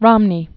(rŏmnē)